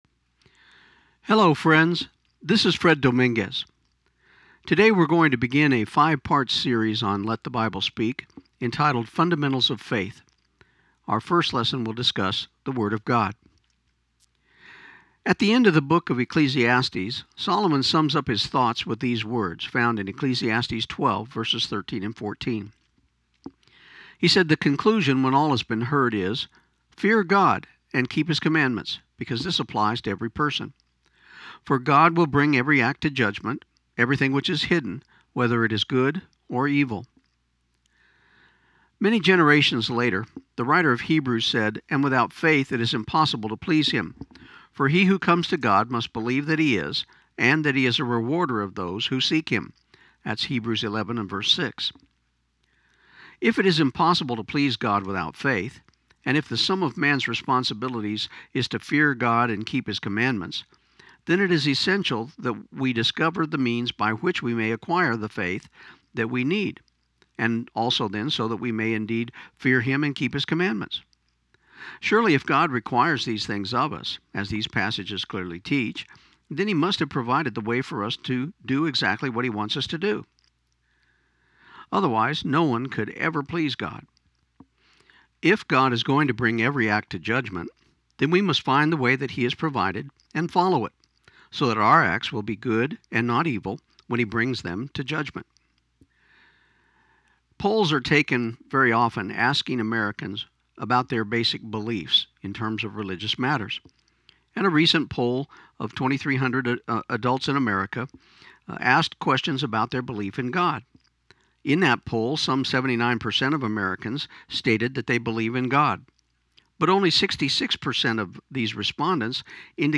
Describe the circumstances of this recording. This program aired on 1400 KIUN AM in Pecos, TX on January 5, 2015.